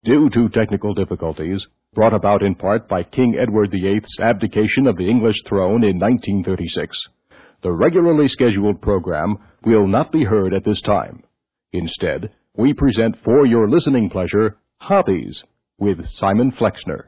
In the meantime, here are some lo-fi versions of the radio programs... these were done in 1978.